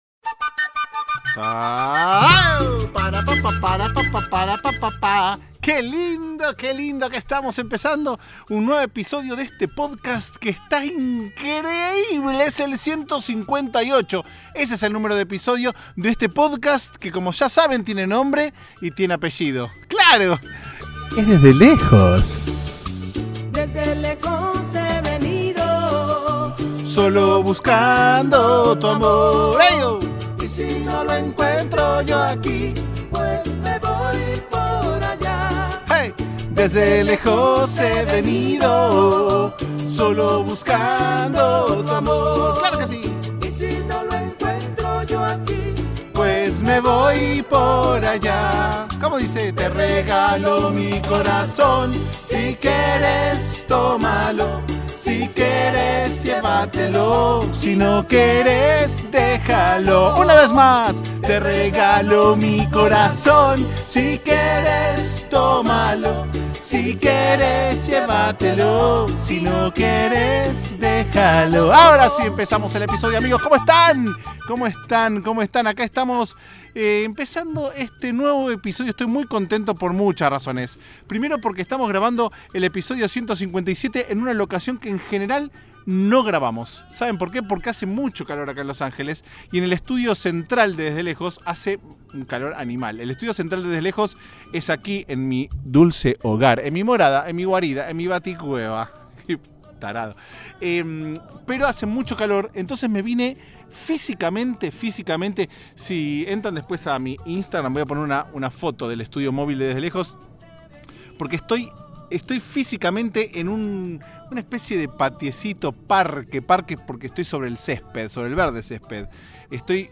Grabado íntegramente en exteriores (literal) este episodio recorre los intrincados caminos de la nostalgia suburMENTIRA!